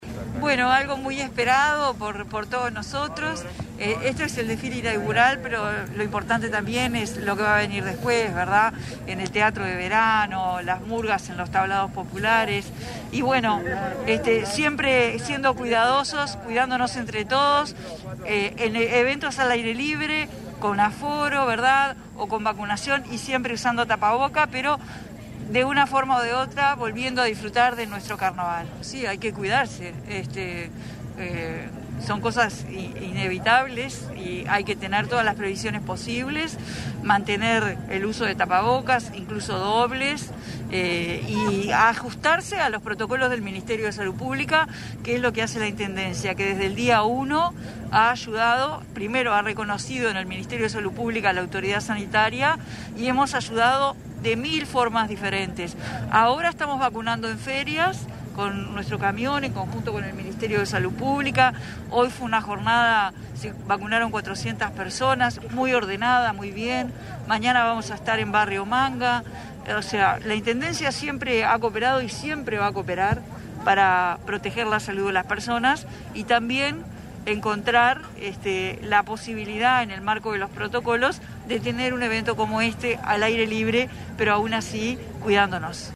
Carolina Cosse, se hizo presente en el tradicional Desfile Inaugural de Carnaval, fijado para este jueves 20 de enero en el paseo de las Canteras del Parque Rodó.